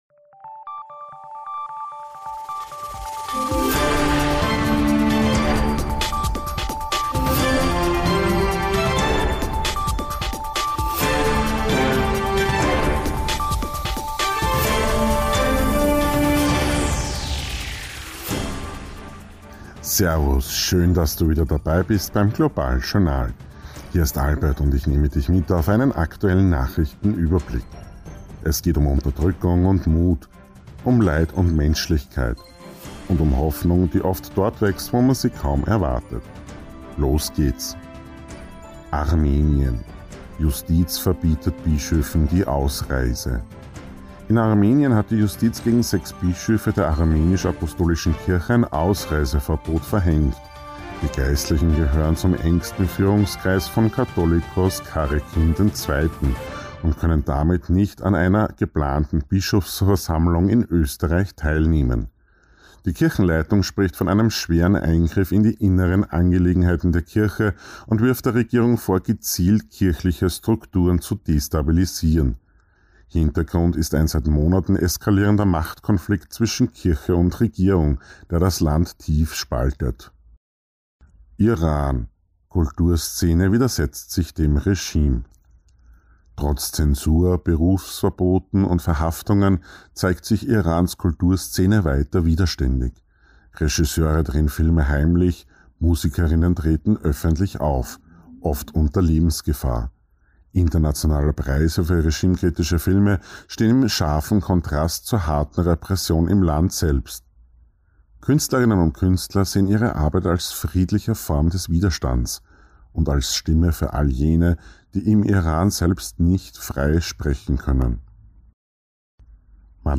News Update Februar 2026